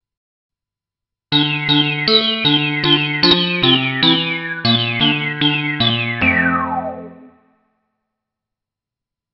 This is an impression of the first official commercial sound synthesizer I ever had the pleasure of playing, the Moog/Realistic, in an impression from Bristol, which is Linux software to emulate dozens of classic synthesizers:
Example sound (with reverb added by the jack-rack plugin stereo plate), in 96kbps stereo mp3 (0.1 MB file).